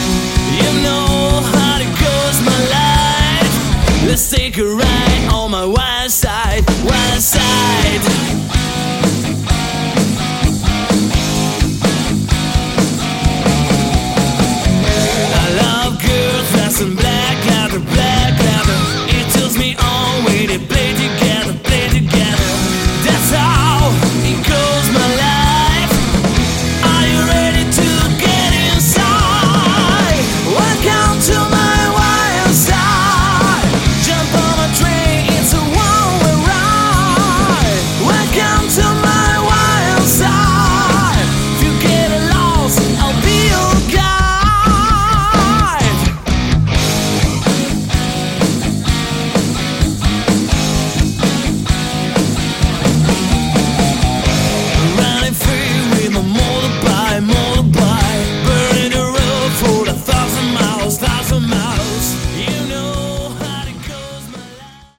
Category: Hard Rock
Vocals
Guitar
Bass
Drum